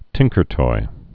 (tĭngkər-toi)